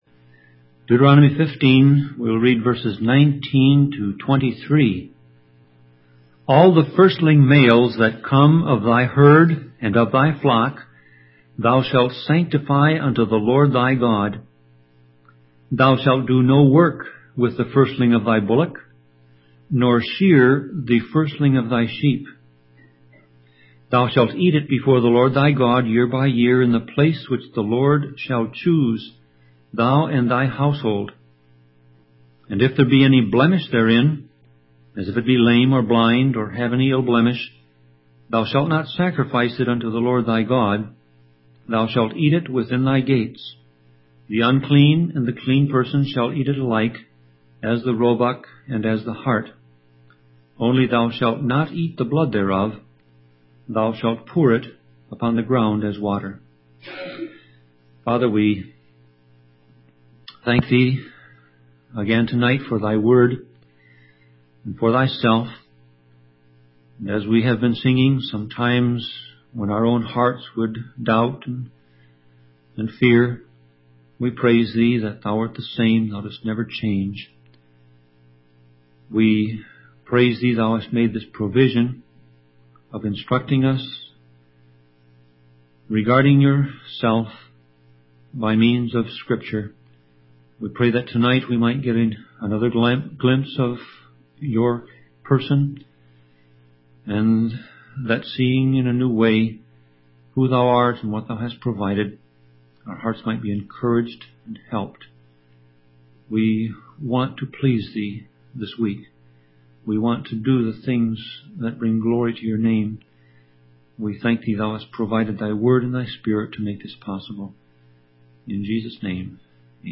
Sermon Audio Passage: Deuteronomy 15:19-23 Service Type